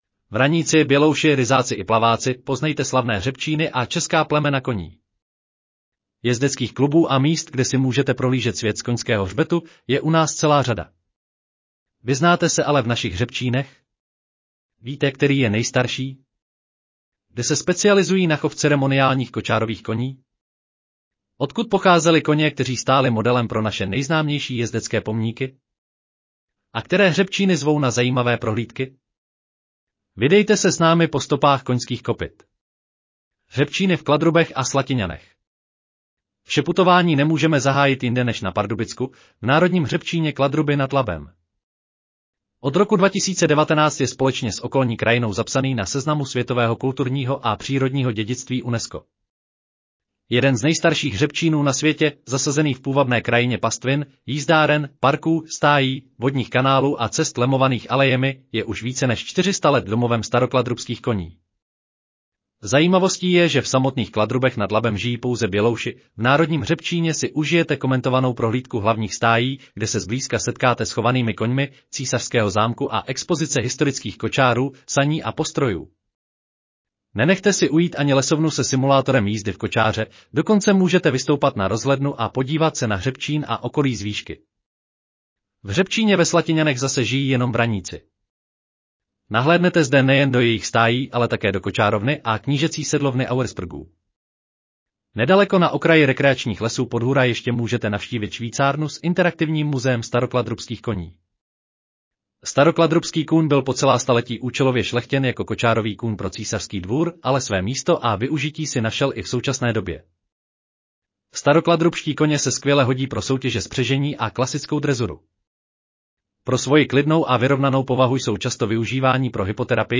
Audio verze článku Vraníci, bělouši, ryzáci i plaváci: poznejte slavné hřebčíny a česká plemena koní